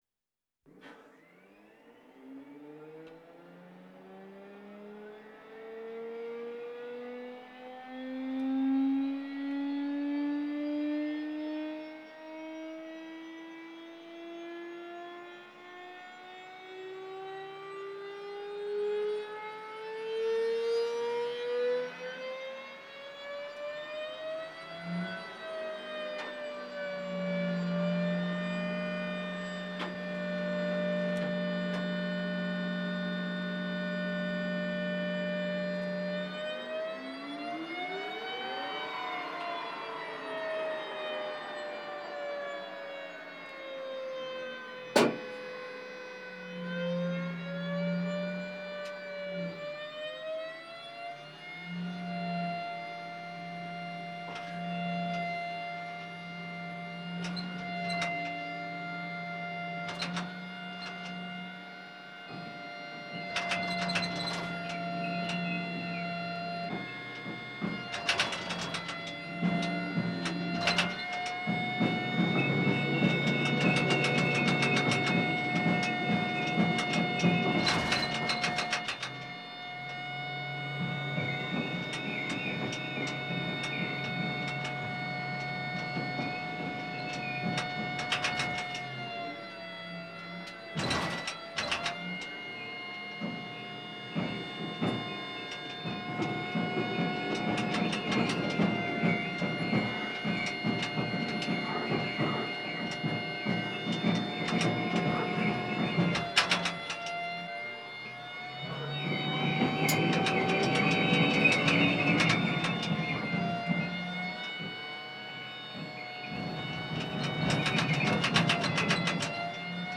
transport
Tank M1 Moving Interior Perspective